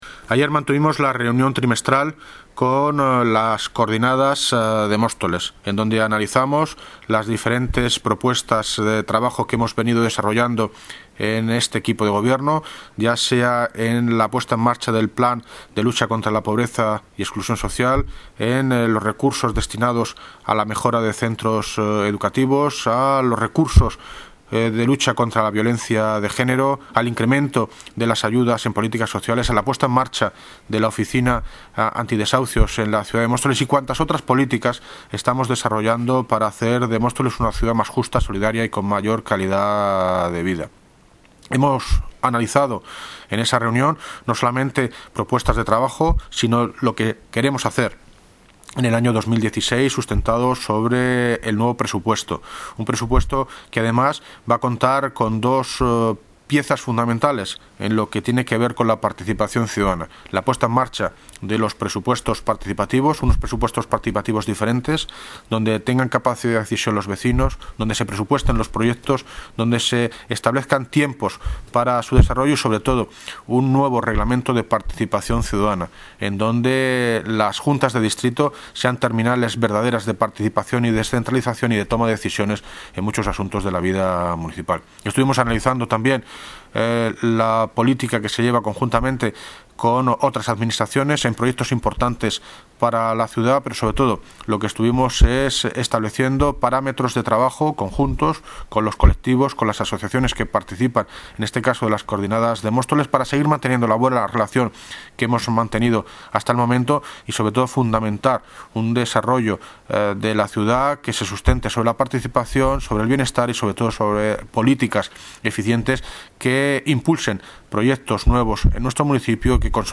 Audio - David Lucas (Alcalde de Móstoles) sobre reunión trimestral Coordibndas de Vecinos